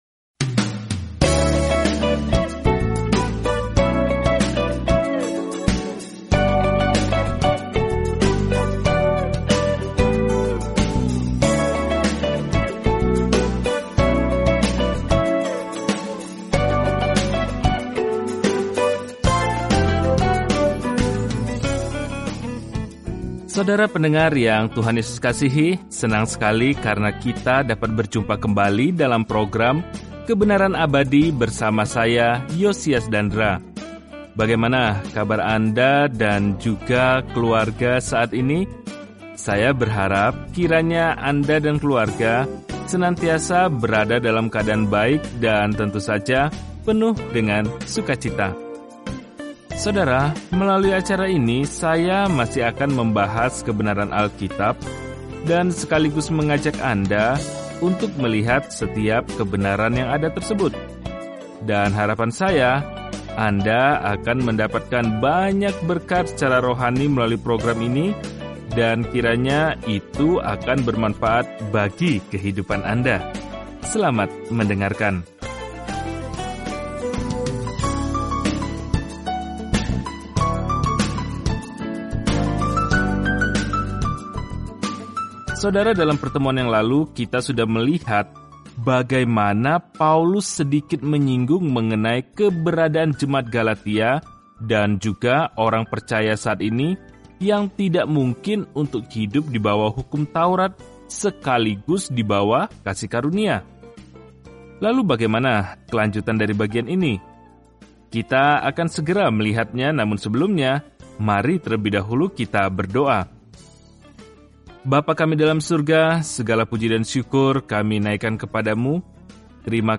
Firman Tuhan, Alkitab Galatia 4:25-31 Hari 12 Mulai Rencana ini Hari 14 Tentang Rencana ini “Hanya melalui iman” kita diselamatkan, bukan melalui apa pun yang kita lakukan untuk layak menerima anugerah keselamatan – itulah pesan yang jelas dan langsung dari surat kepada jemaat Galatia. Jelajahi Galatia setiap hari sambil mendengarkan pelajaran audio dan membaca ayat-ayat tertentu dari firman Tuhan.